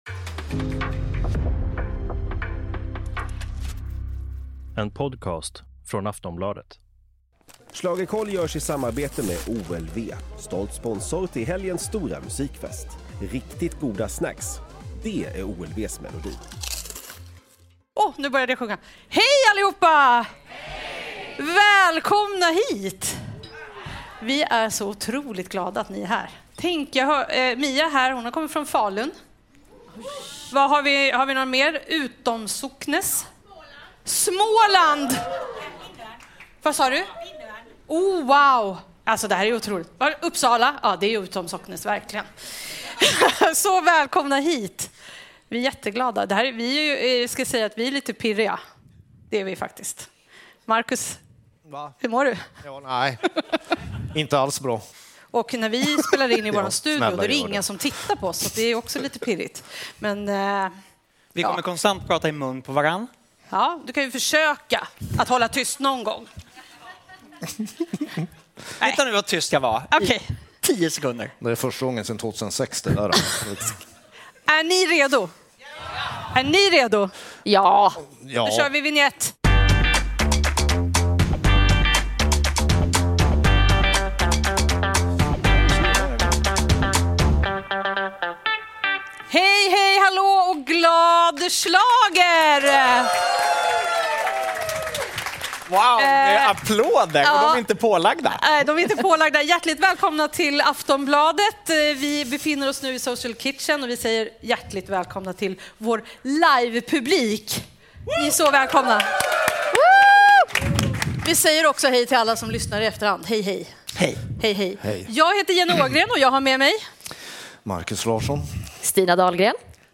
Äntligen är Melodifestivalen 2025 här! Vi träffar vår härliga publik live och kör ett långt uppsnack inför tävlingen. Som vanligt snackar vi högt och lågt om allt som händer i Mello - häng med!